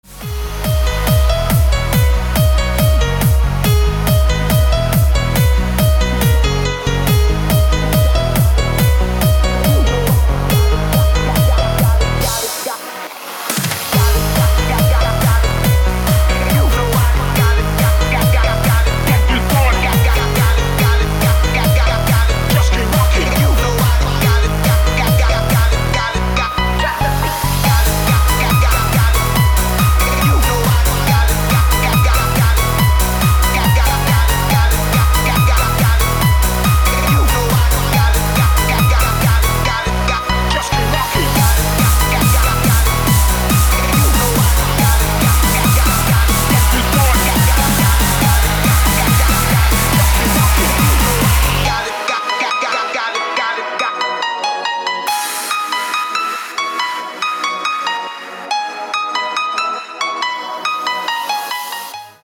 • Качество: 256, Stereo
ритмичные
громкие
dance
EDM
электронная музыка
клавишные
club
пианино